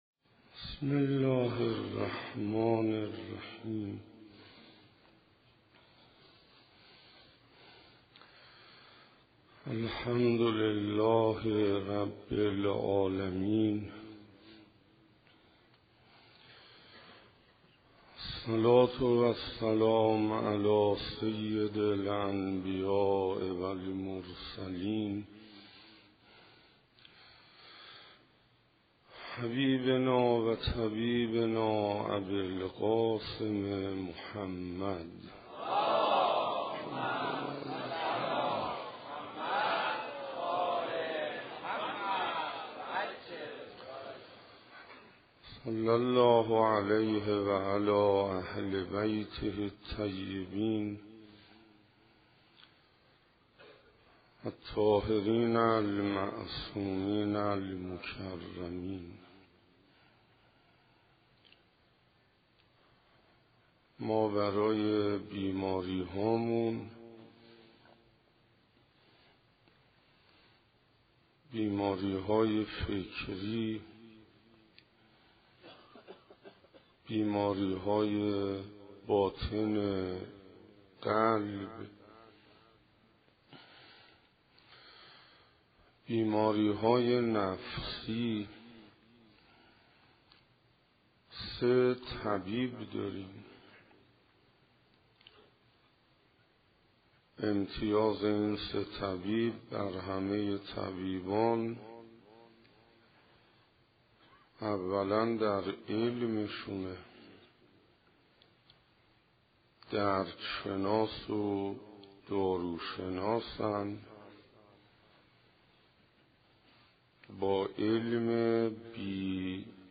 روز ششم محرم 95_ حسینیه هدایت_سخنرانی